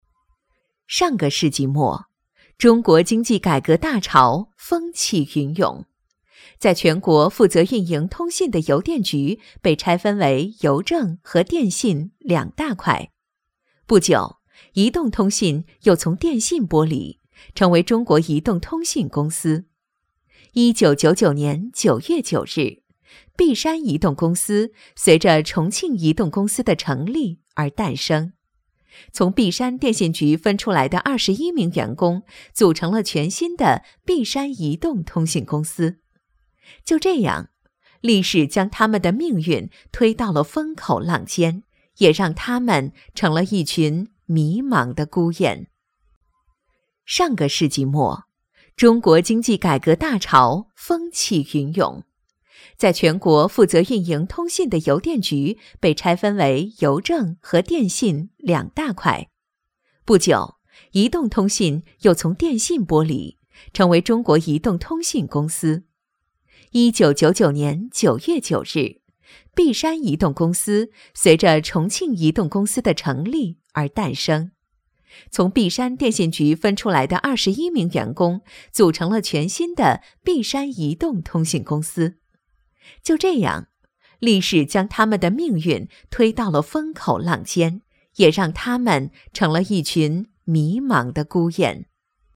• 女S12 国语 女声 专题片-乘风破浪的航行-企业专题片-温和 沉稳|积极向上|亲切甜美